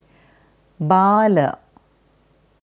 Dieses Wort auf Devanagari schreibt man बाल, in der IAST Schreibweise bāla. Hier hörst du, wie man Bala sagt.